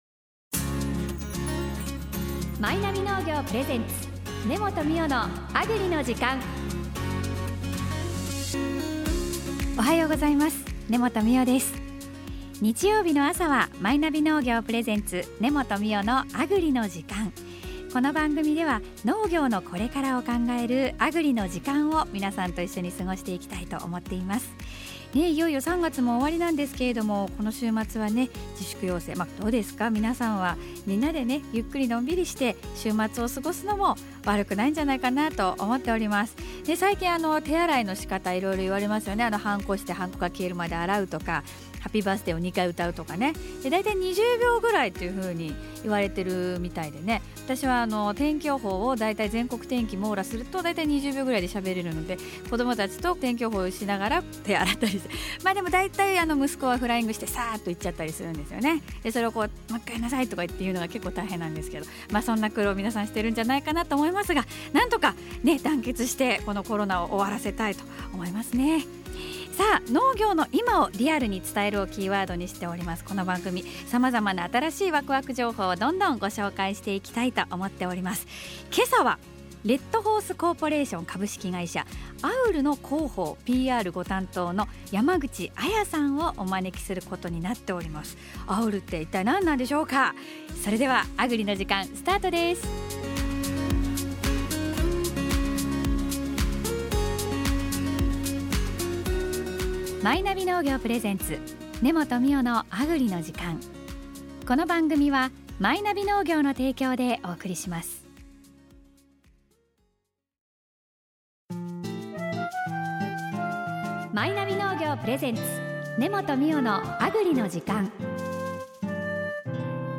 素敵な笑顔に、スタジオがパッと華やぎました。